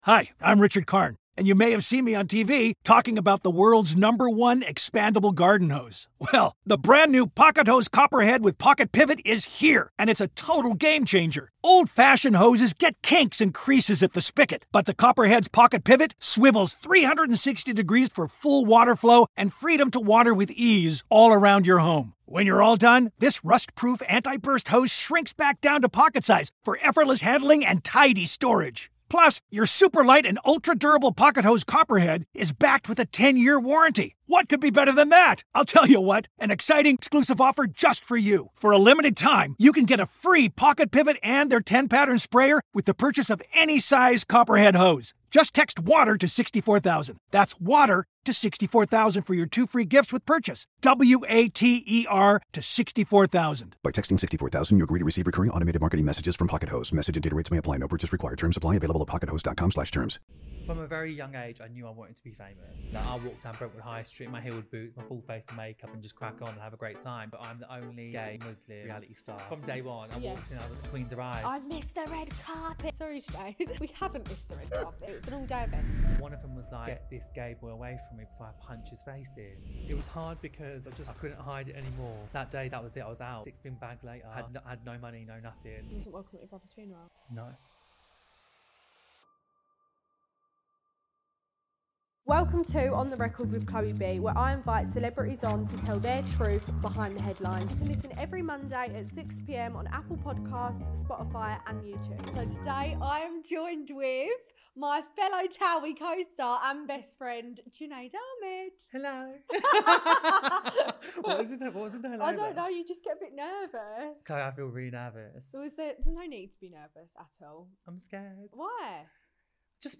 🦷 Special thanks to Blank Box Studio for their amazing studio space